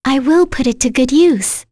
Kirze-vox-get_02.wav